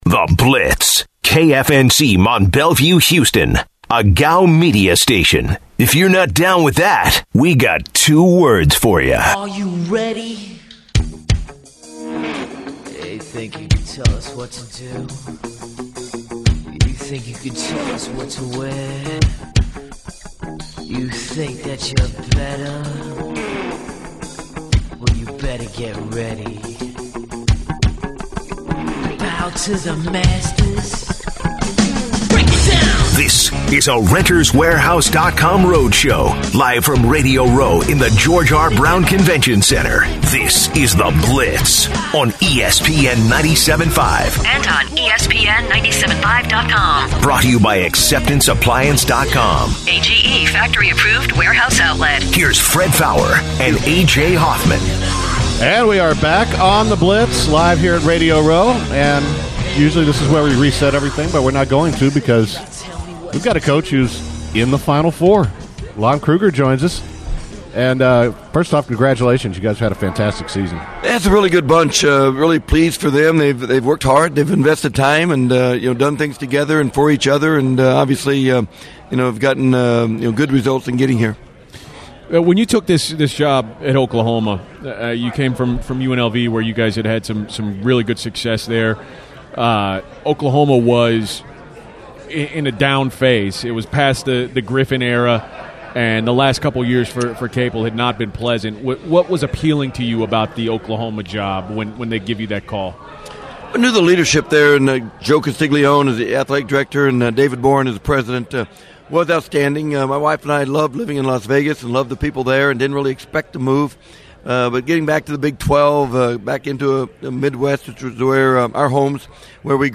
live from Westwood One Radio Row in Downtown Houston.